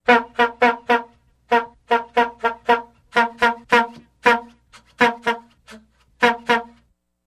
Car Horn Old Air Horn Honk